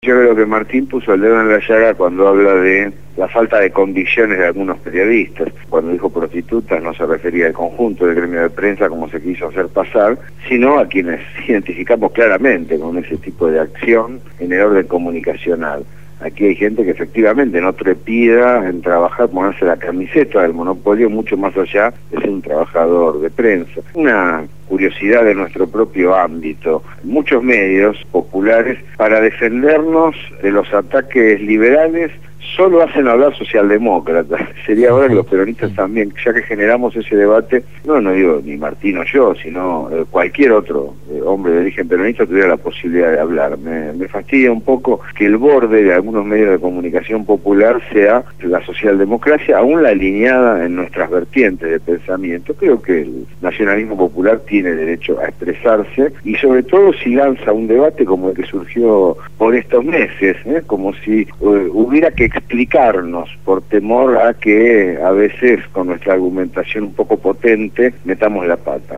entrevistaron